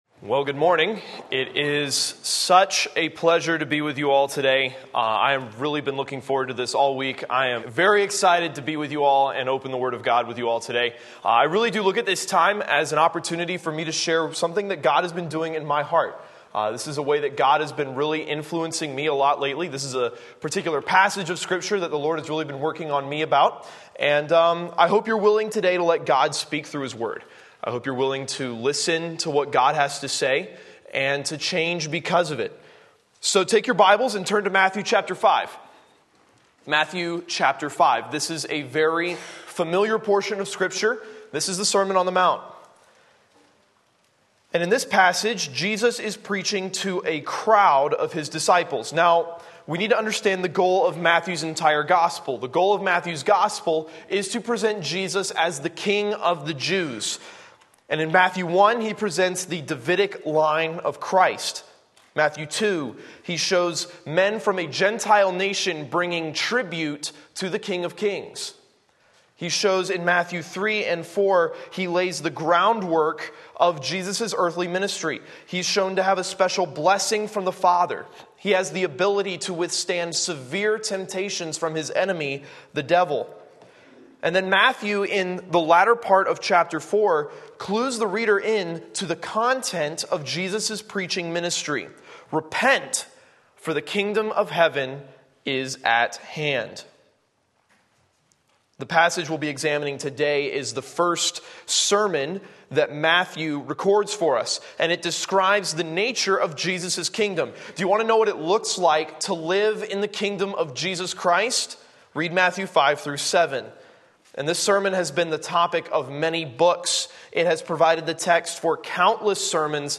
Sermon Link
6 Sunday Morning Service